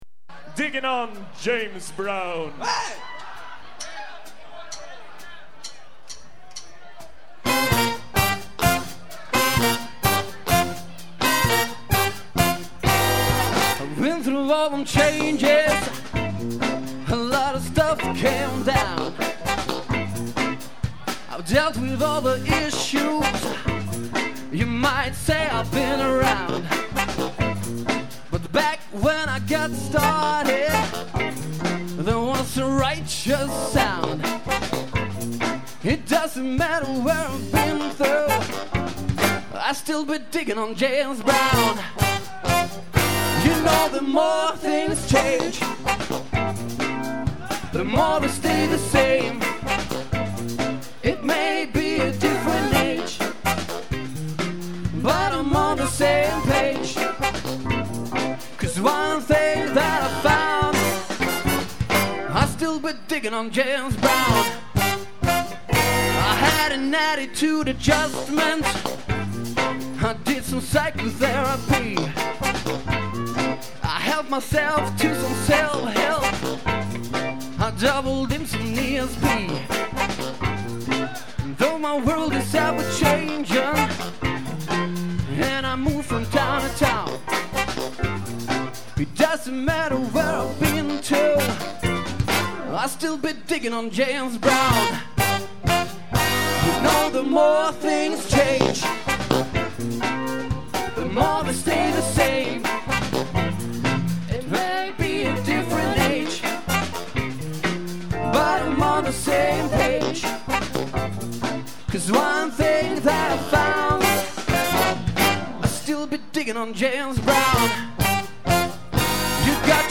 Plats: Baljan, Kårallen
Tillställning: Valla Saucer Rennen 2000
Trummor
Gitarr
Keyboard
Trombon